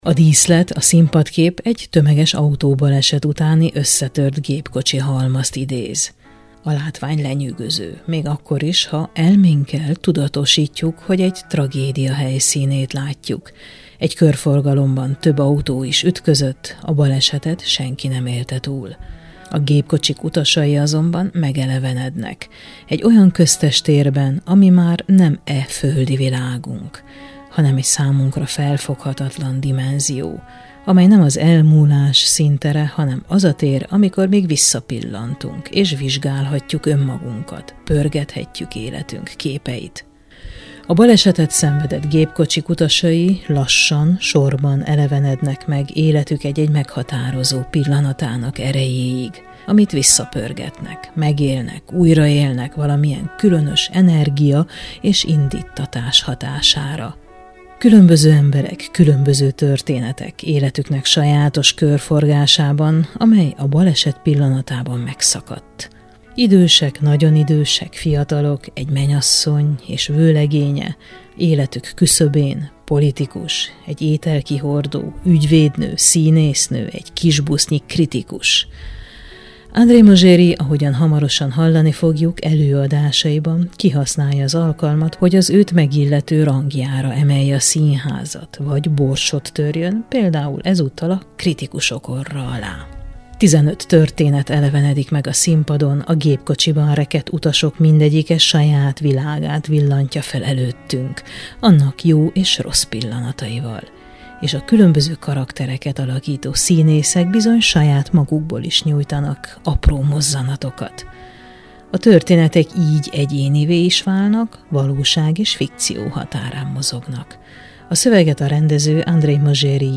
Ahol megáll az idő: Beszélgetés az Emberek és istenek című produkcióról